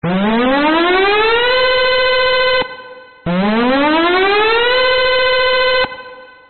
参考までに、通常の上昇音のほうも作ってみたので、聞き比べてみてください。
きいてみる(上昇音)
それに比べ、上昇音の緊張感といったら！